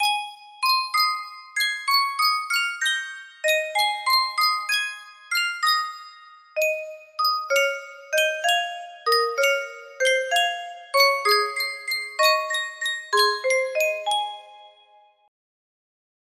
Yunsheng Music Box - Mary Mary Quite Contrary 4767 music box melody
Full range 60